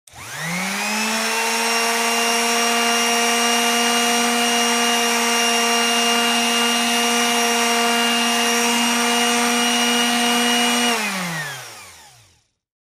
Orbital sander operating at variable speeds. Tools, Hand Sander, Tool Motor, Sander